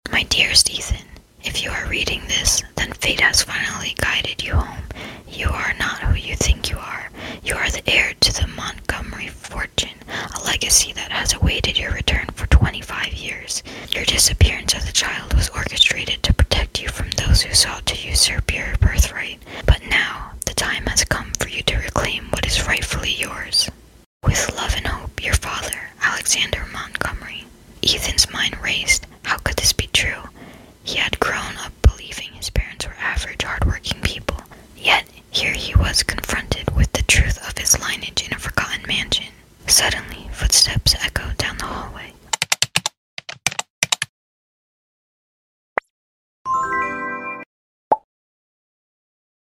ASMR | Pt.3 Of The sound effects free download
ASMR | Pt.3 Of The AI Generated Short Story The Forgotten Heir (Soft Whispering)